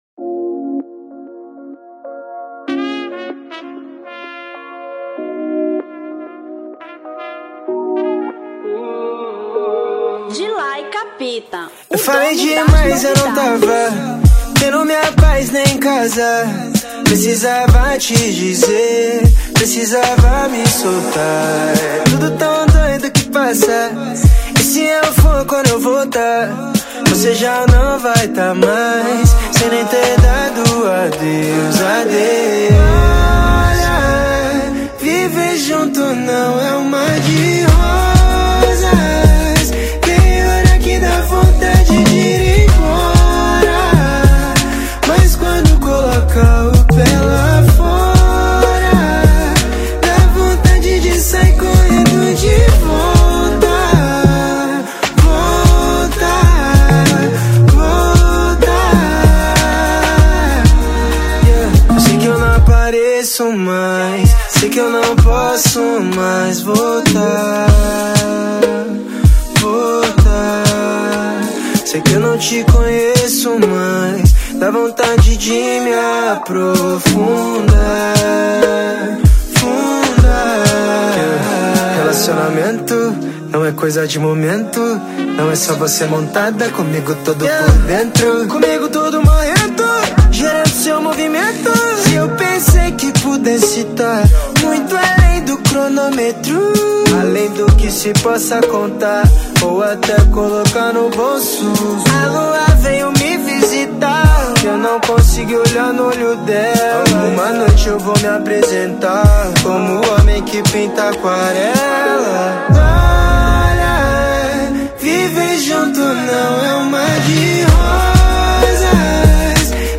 R&B 2025